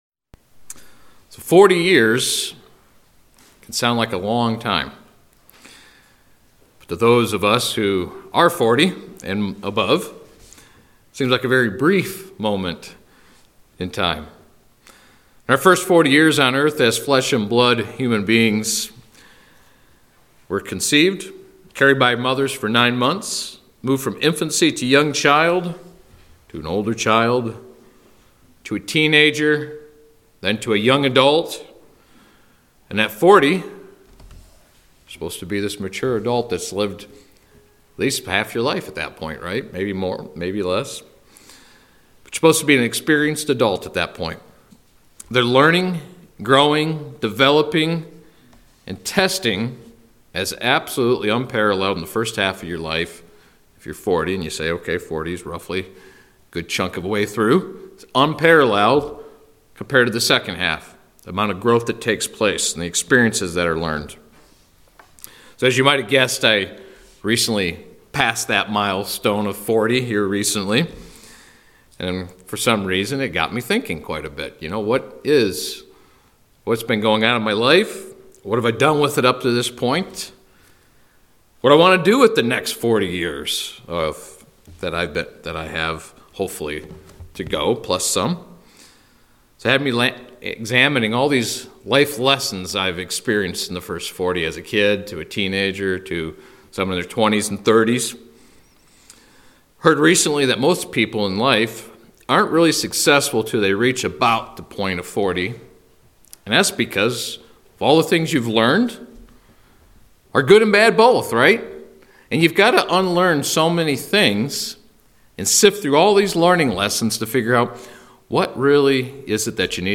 In this sermon we will go over a few things that I have realized in my life and share some of my experiences to help others as they continue their walk with God.